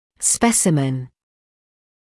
[‘spesɪmən][‘спэсимэн]образец, проба